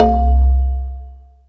Long Block (JW2).wav